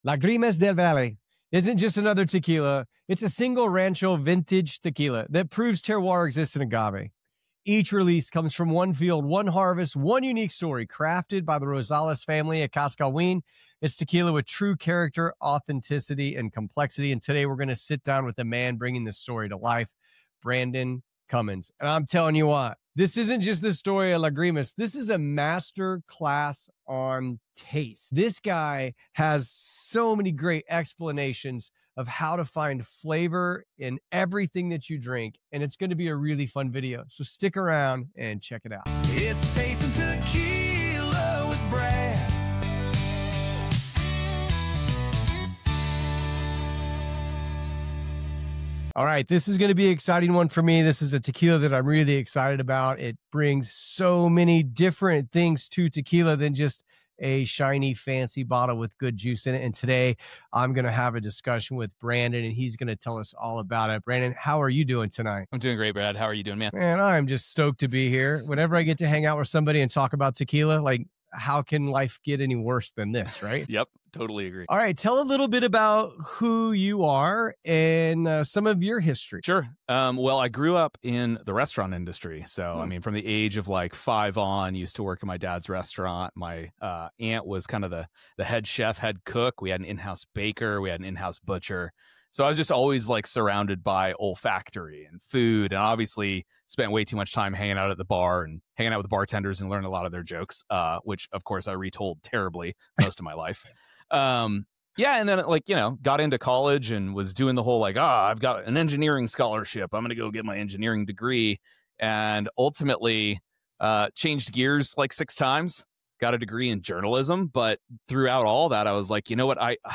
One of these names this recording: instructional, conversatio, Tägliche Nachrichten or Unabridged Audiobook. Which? conversatio